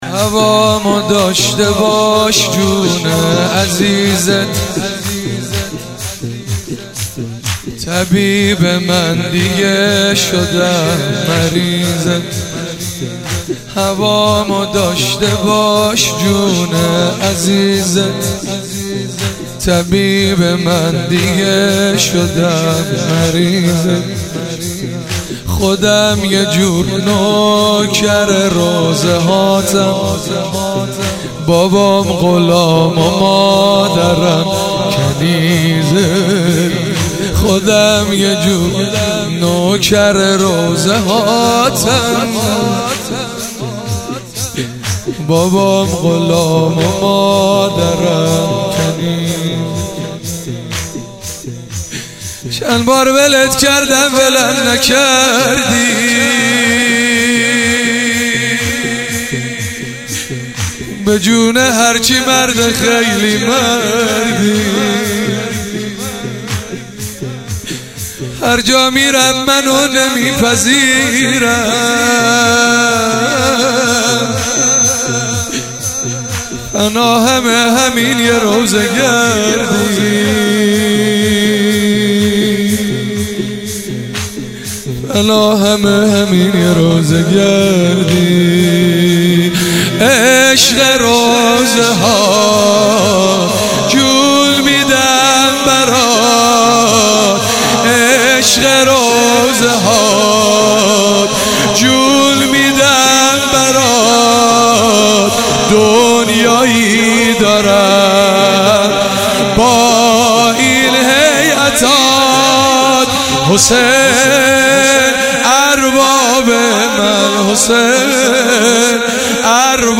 عنوان مراسم شب اول ماه مبارک رمضان
شور